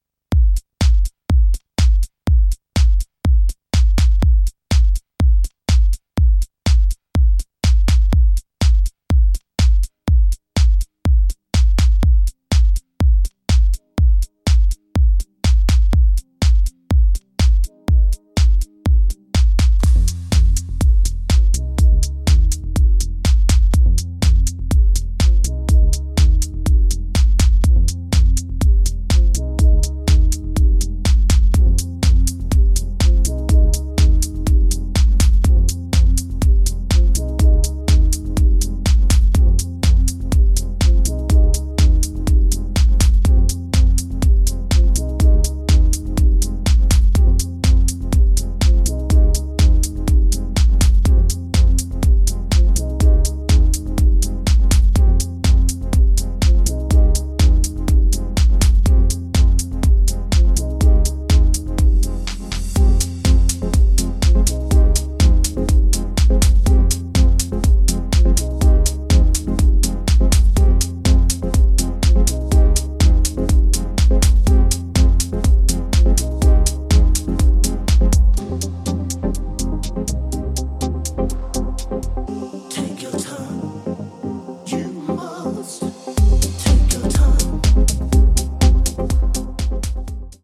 ソリッドなトラックにウォームな奥行きを与えています！